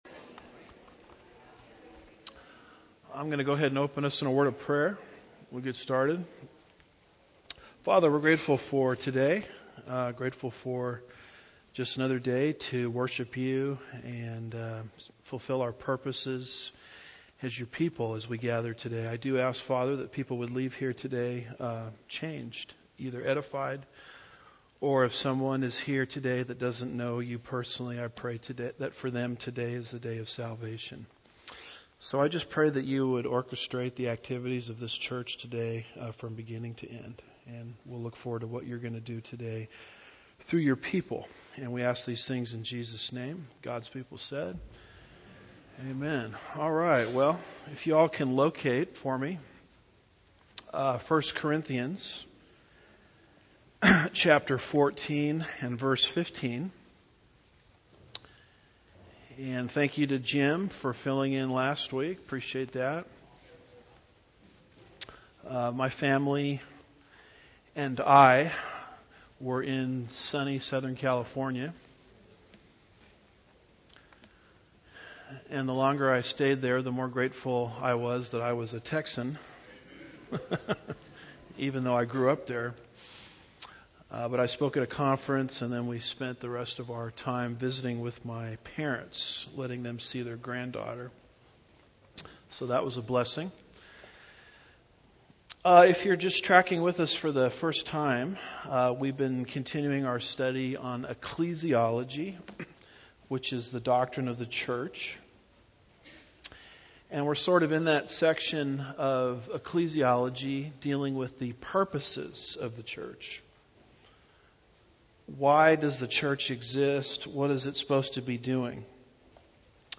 Lesson 32